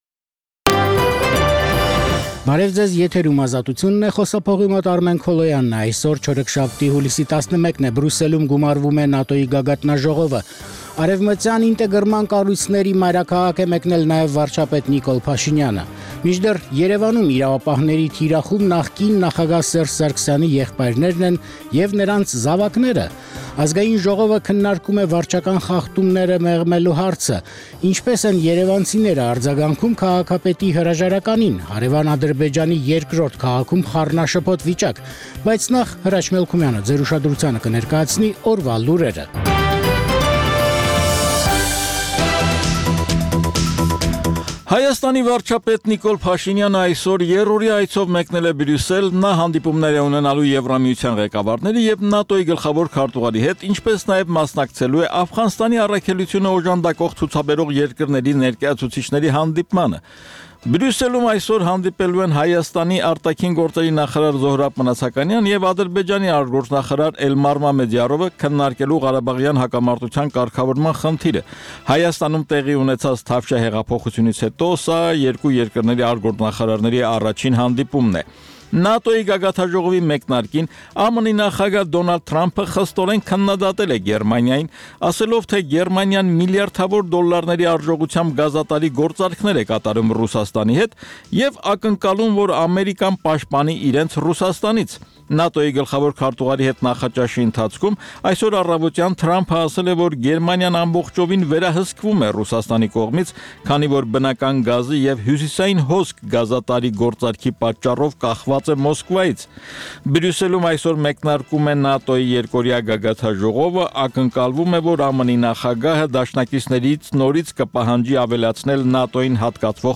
«Ազատություն» ռադիոկայանի ցերեկային ծրագիր